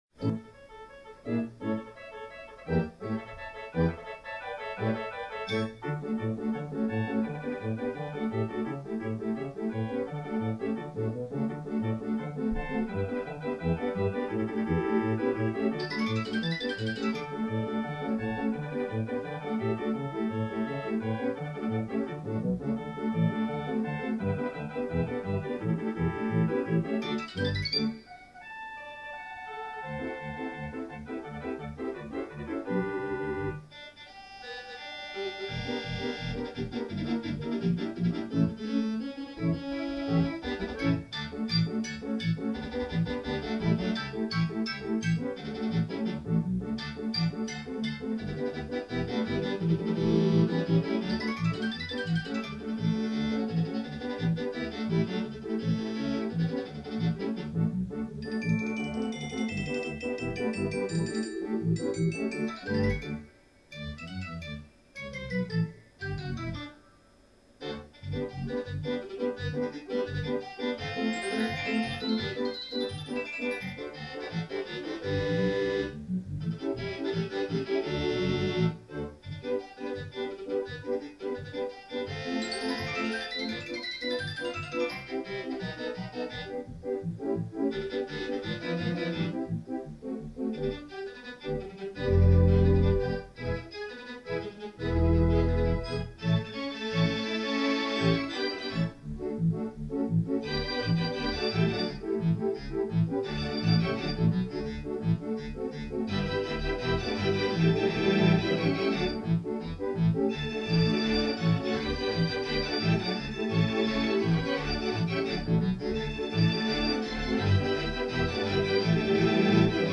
theatre organ